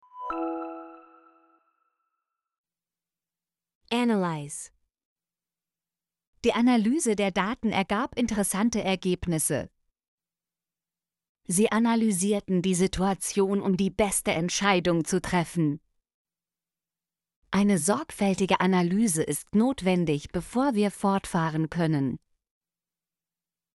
analyse - Example Sentences & Pronunciation, German Frequency List